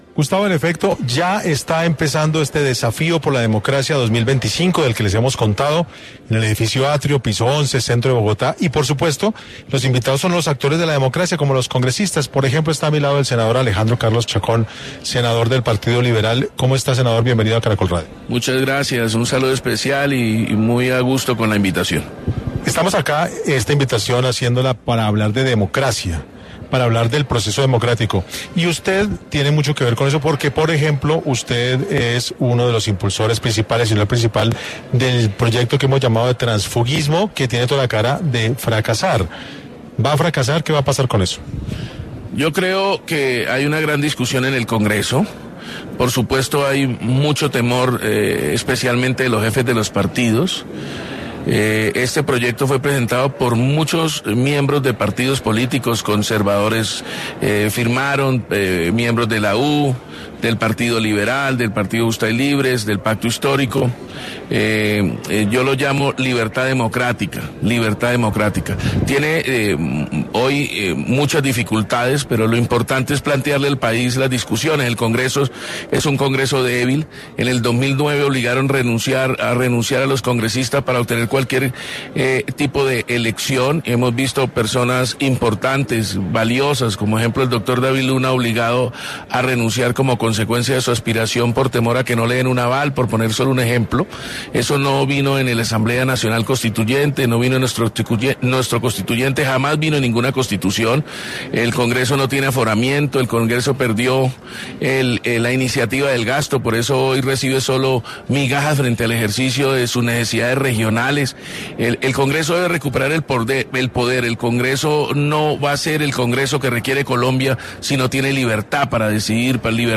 En 6AM, estuvo el Congresista quien habló de uno de los debates políticos más importantes en este momento: la reforma laboral.